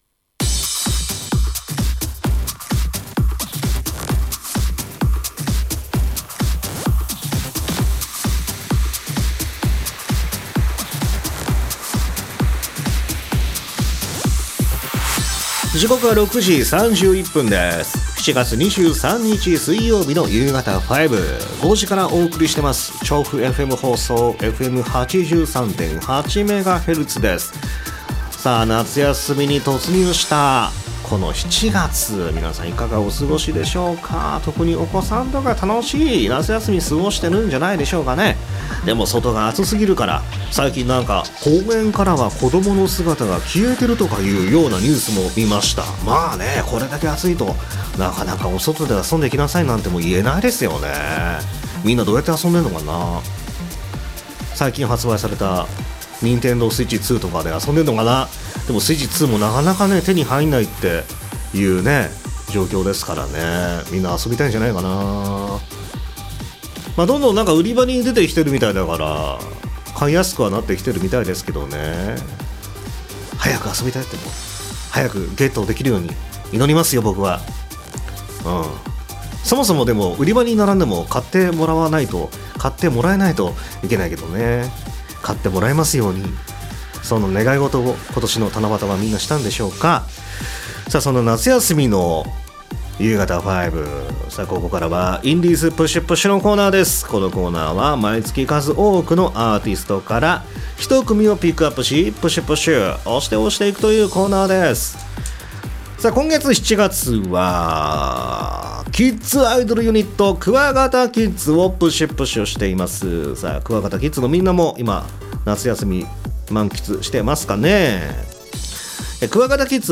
長崎県の川棚町教育委員会、オオクワガタ研究会とハッピーヴォイス事務所がコラボで結成したキッズアイドルユニット。